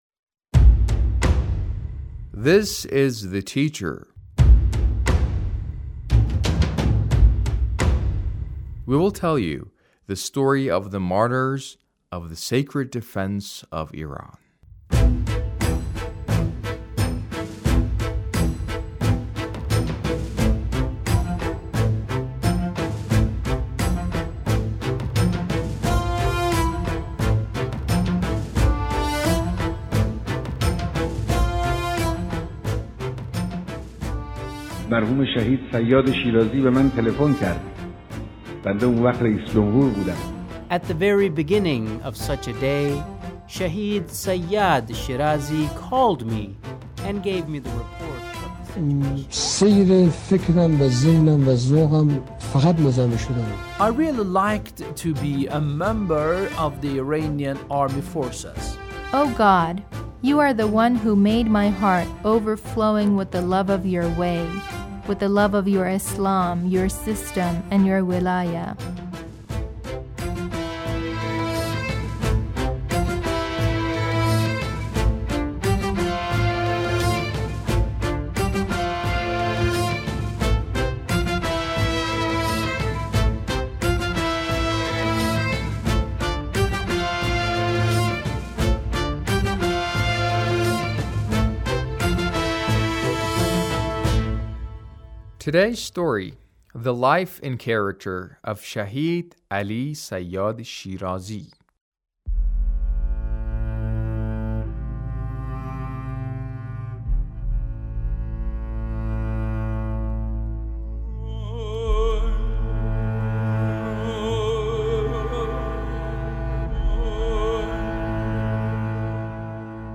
A radio documentary on the life of Shahid Ali Sayyad Shirazi- Part 1